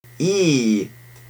• /iː/ is close front unrounded [iː] (